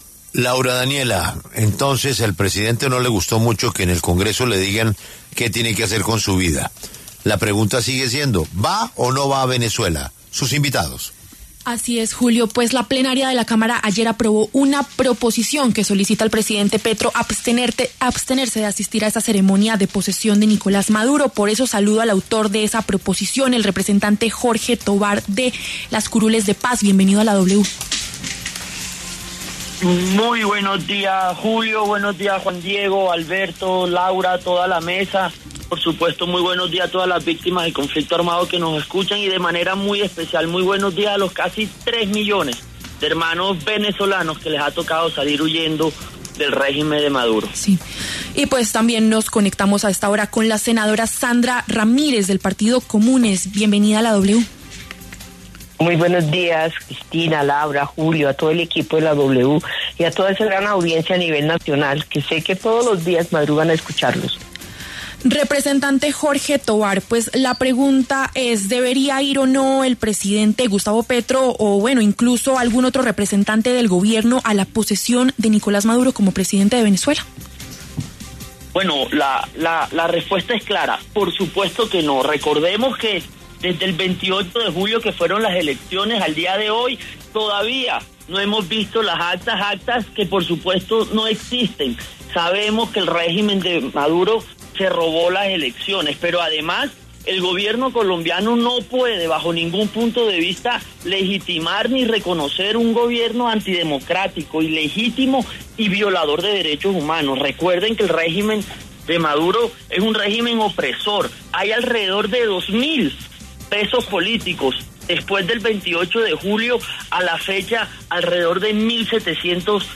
Debate de congresistas: ¿Petro debe acudir a la posesión de Nicolás Maduro en Venezuela?
El representante Jorge Tovar, de la Curules de Paz (CITREP), y la senadora Sandra Ramírez, del Partido Comunes, debatieron en La W.